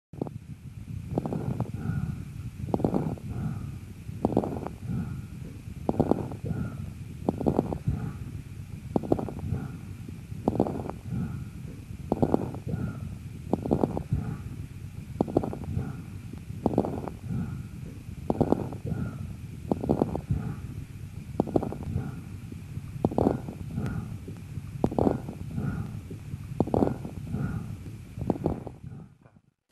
Жесткое дыхание: случай пациентки с хроническим бронхитом